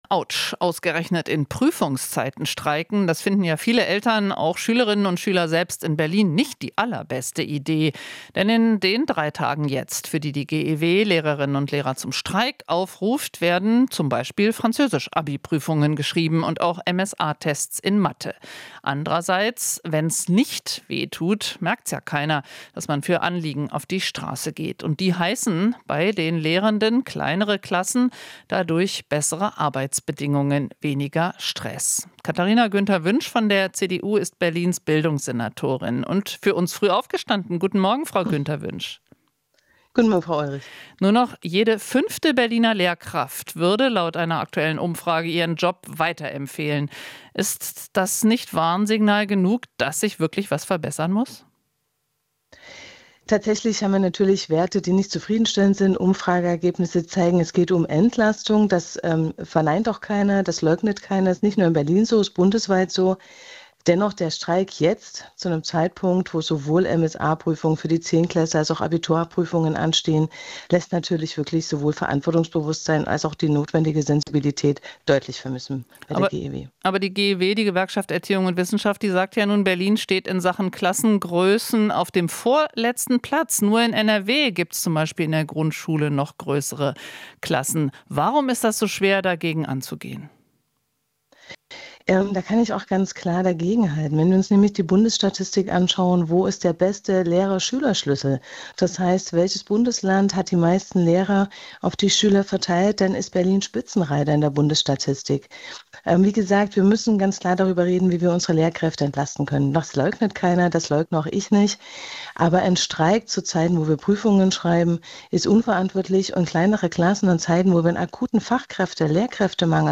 Interview - Senatorin Günther-Wünsch (CDU) kritisiert Warnstreik an Schulen